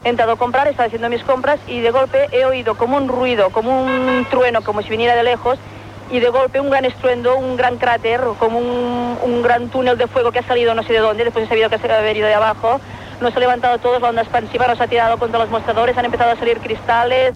Declaració d'una testimoni de l'atemptat d'Hipercor a Barcelona, poc després de l'explosió d'una bomba a dins del seu aparcament.
Informatiu